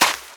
STEPS Sand, Run 27.wav